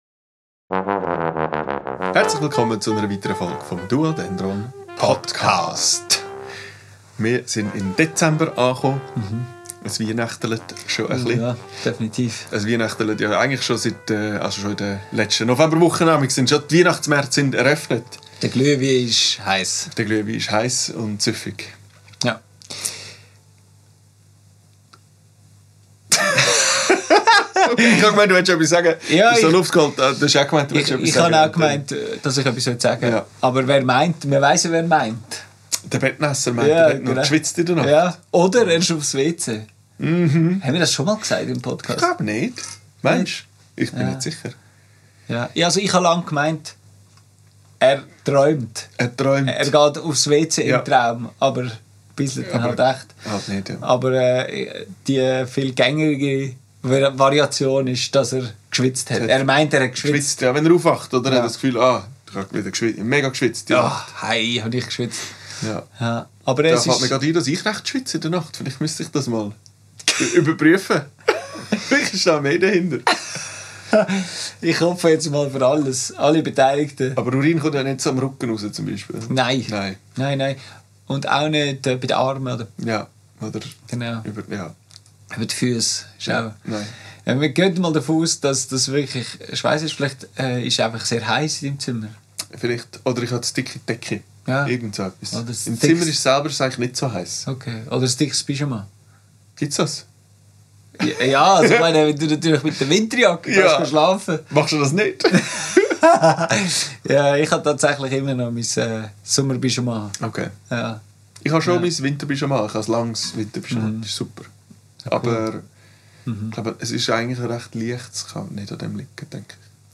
Wir erzählen von der bevorstehenden duodendron Retraite, von laufenden Prozessen und vom Verschwimmen der Tage im Dezember. Aufgenommen am 25.11.2025 im Atelier